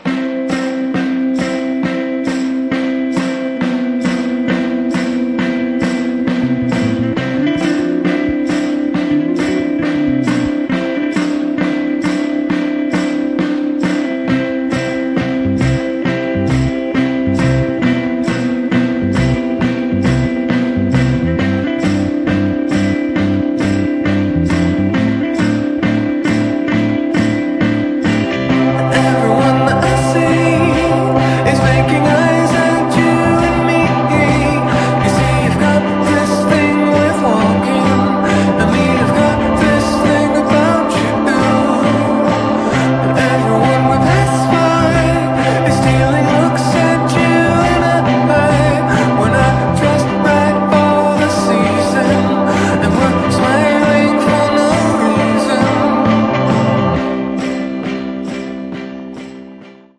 czy też lekko nostalgiczna i wyraźnie brit-popowa piosenka
warstwa dźwiękowa na płycie